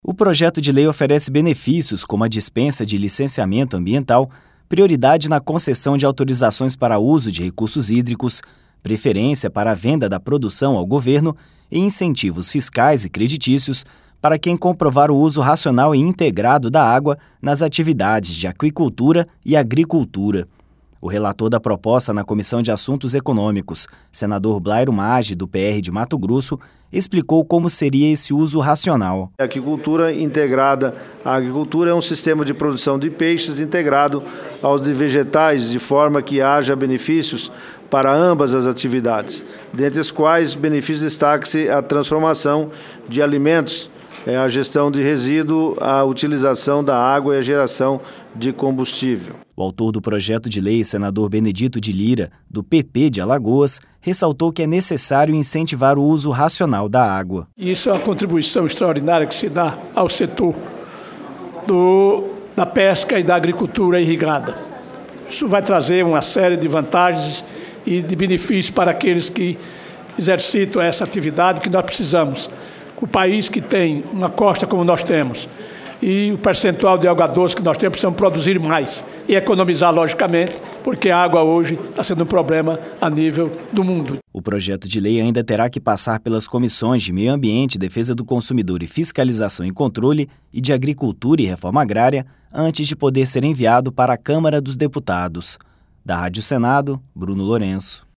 Rádio Senado
O relator da proposta na Comissão de Assuntos Econômicos, senador Blairo Maggi, do PR de Mato Grosso, explicou como seria esse uso racional.
(REPÓRTER) O autor do projeto de lei, senador Benedito de Lira, do PP de Alagoas, ressaltou que é necessário incentivar o uso racional da água.